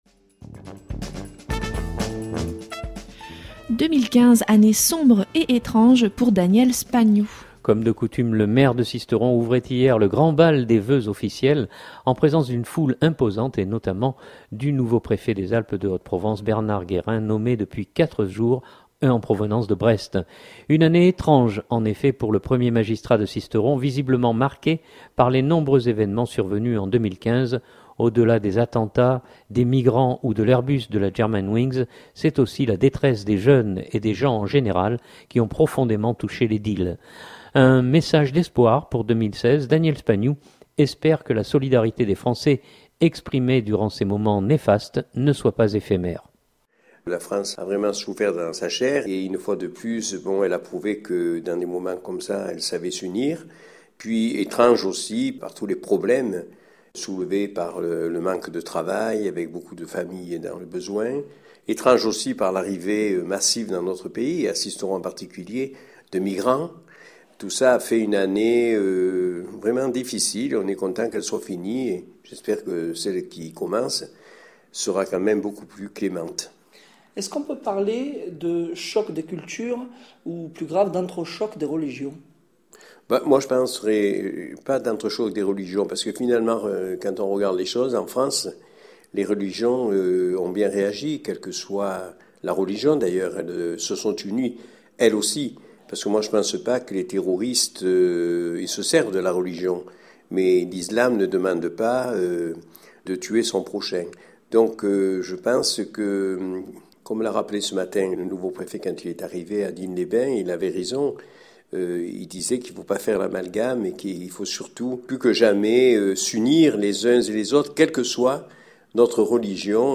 Comme de coutume le Maire de Sisteron ouvrait hier le grand bal des vœux officiels, en présence d’une foule imposante et notamment du nouveau Préfet des Alpes de Haute Provence, Bernard Guérin nommé depuis quatre jours en provenance de Brest.